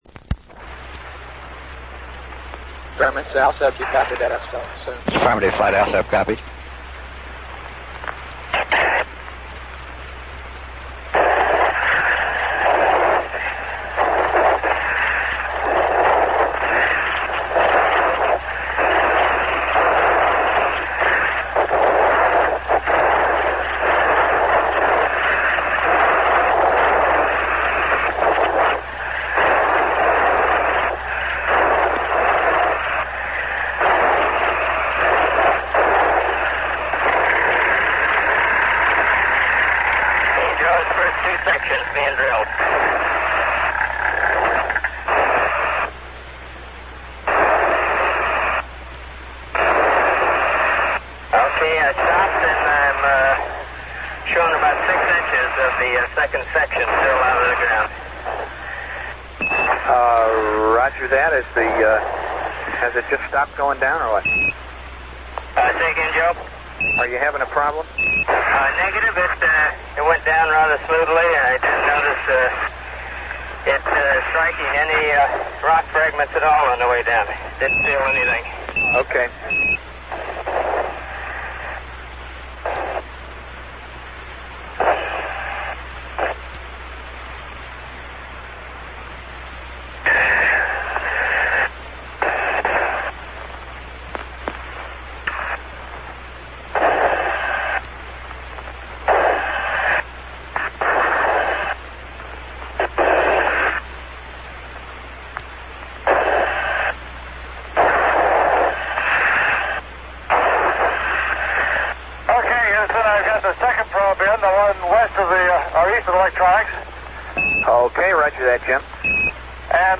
as heard from his console loop in the MOCR.
Fred Haise is heard practising drilling core samples, and Jim Lovell is setting up the ALSEP. Joe Kerwin is the Capcom.